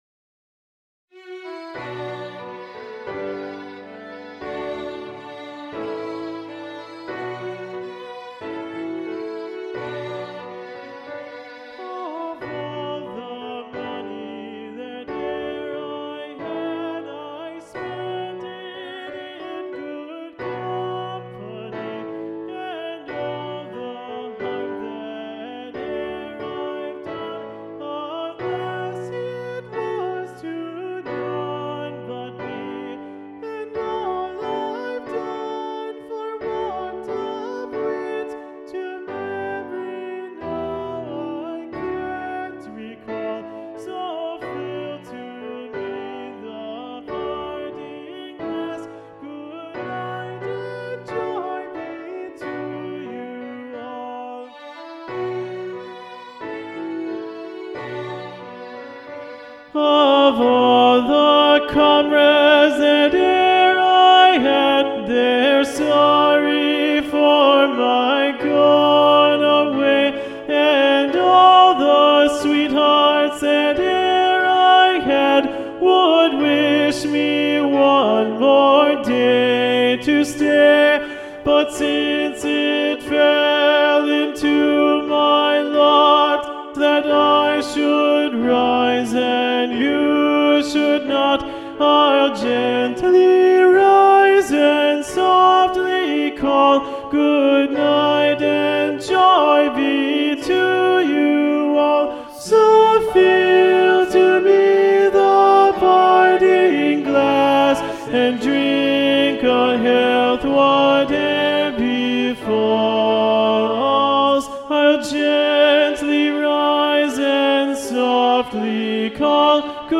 Alto 2